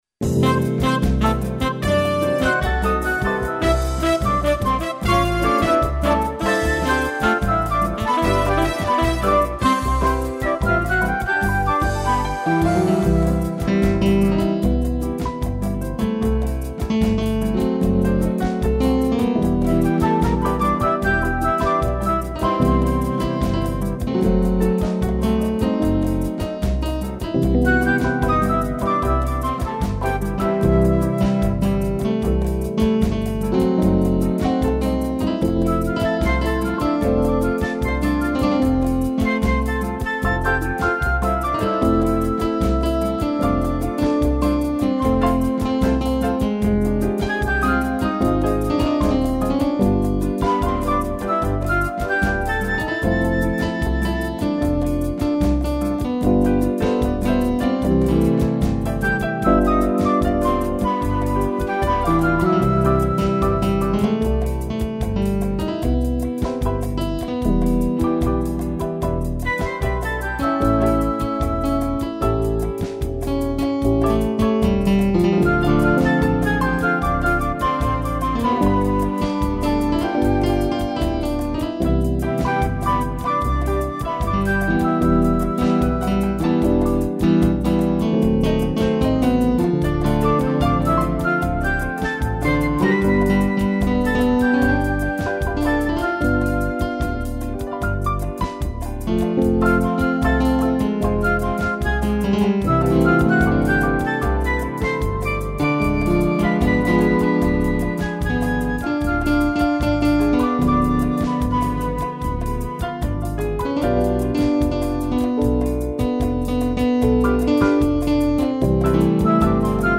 piano e flauta
(instrumental)